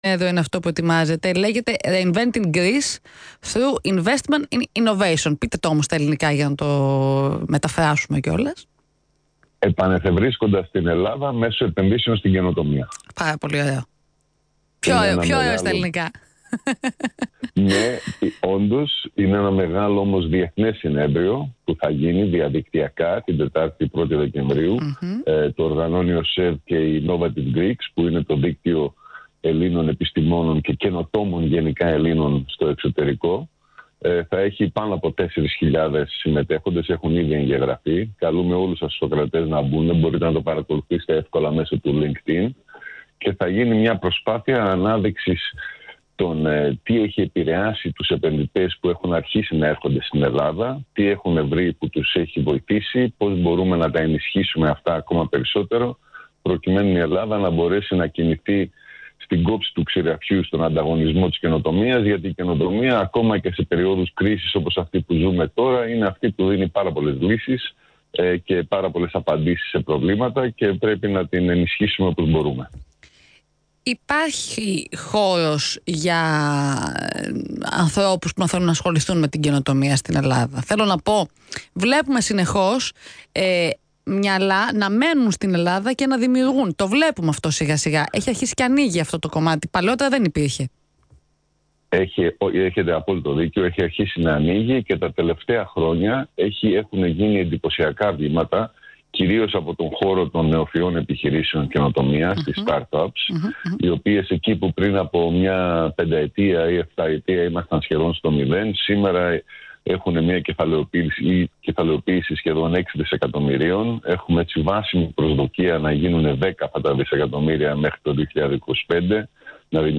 Συνέντευξη
στον Ρ/Σ ALPHARADIO 989